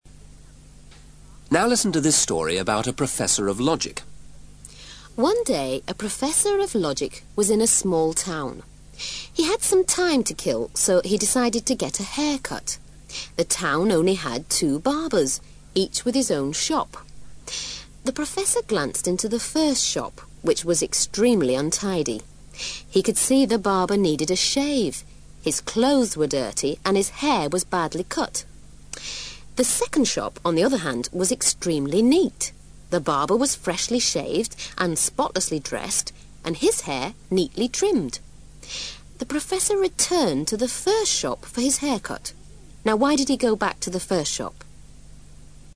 ACTIVITY 313: Now, listen to a psychologist giving you the last problem in logical thinking.